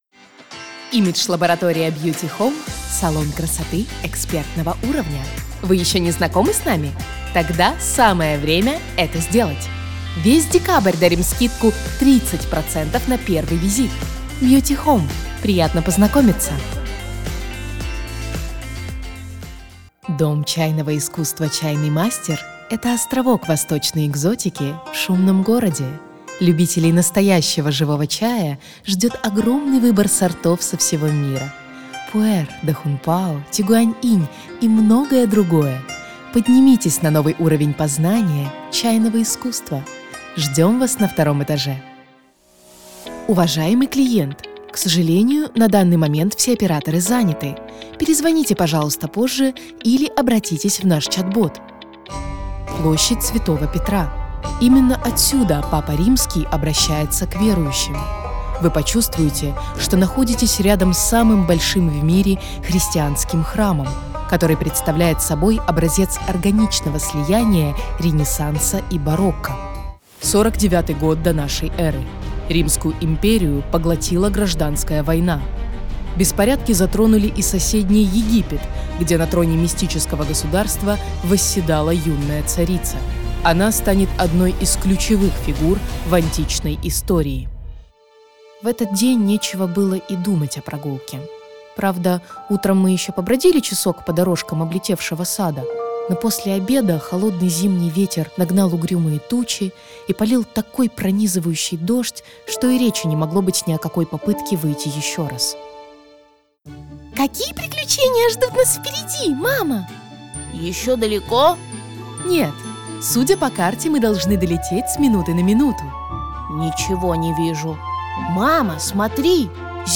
Native Ukrainian & Russian Voice Artist | English & French with Slavic Accent
Russian Multi-Style Reel
My delivery ranges from calm, sophisticated narration and warm commercial tones to expressive, high-energy storytelling.